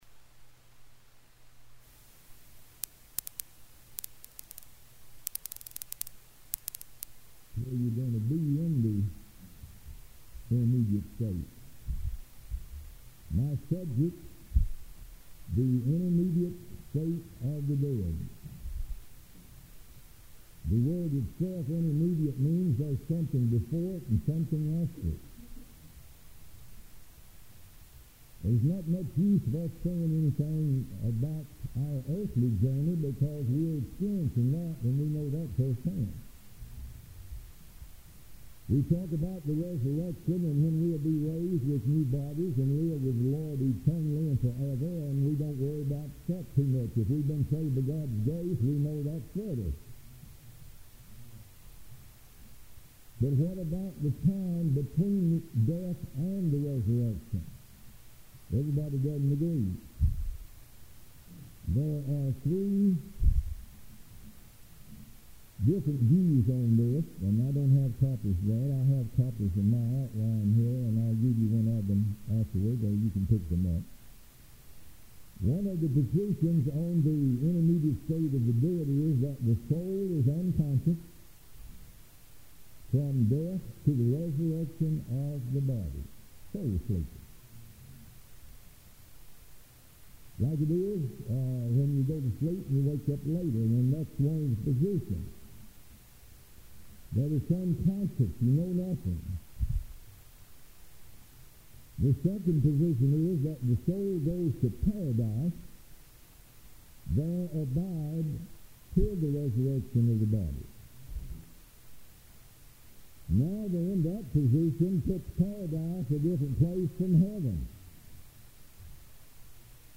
A message from the series "Ministers School 1995."